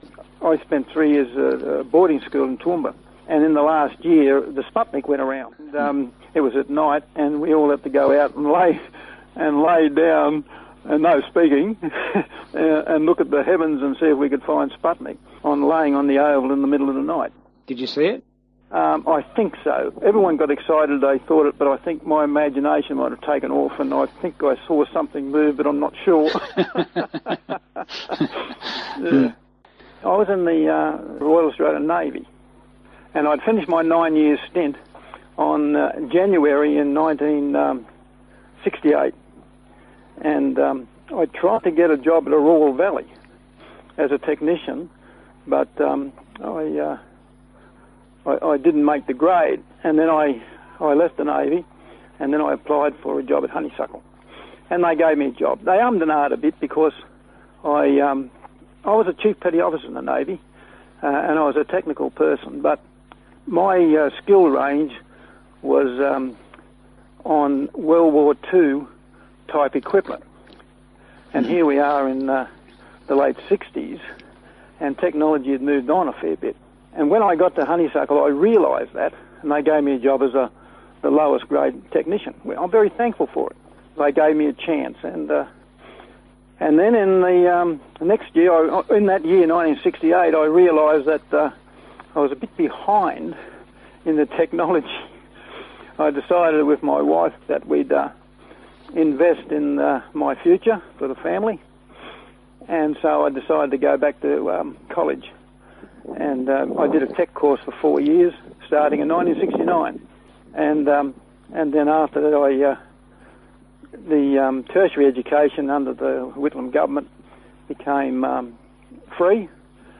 Featured interview